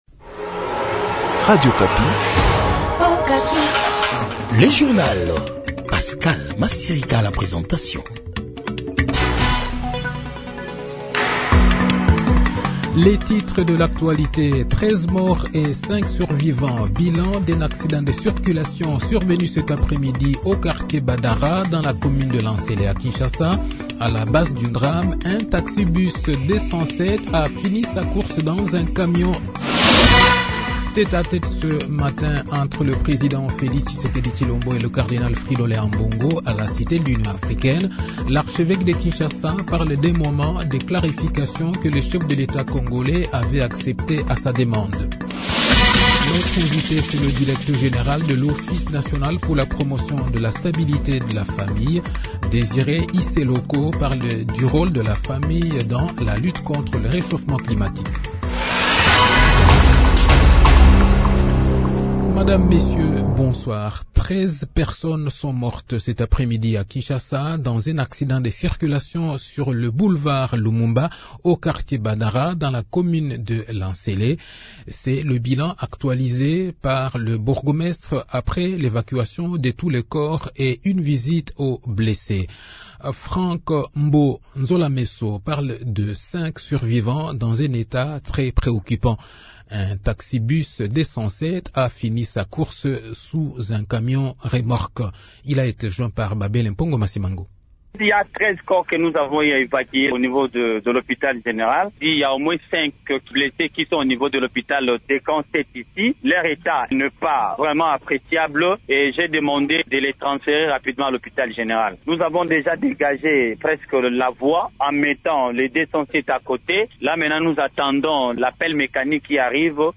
Le journal Soir